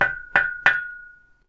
ノックする・叩く音
鉄棒を叩く４
knock_on_iron_tube4.mp3